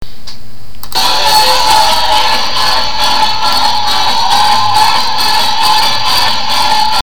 Sorry, but the sample is crappy...